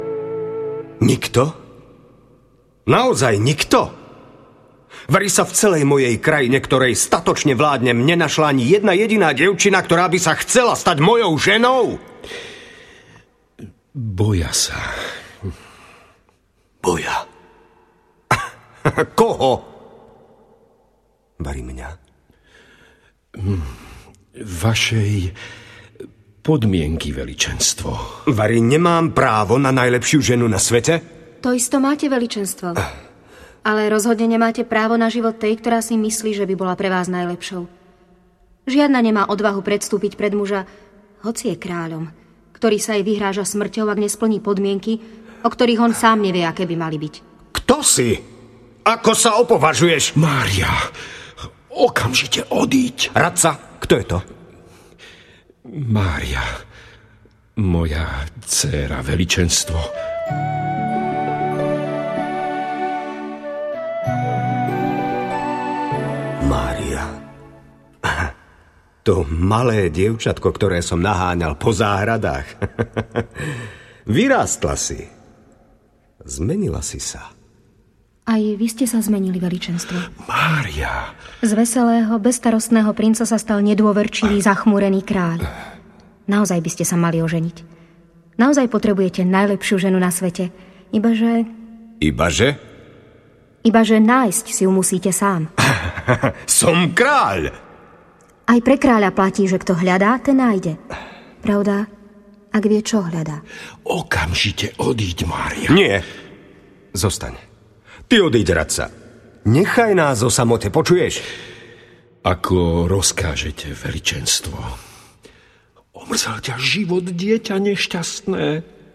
Tri nevesty audiokniha
Ukázka z knihy
tri-nevesty-audiokniha